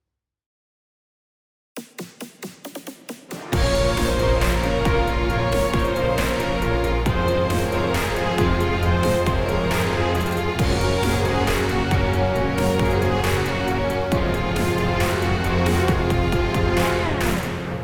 זה סגנון של שירים להיטים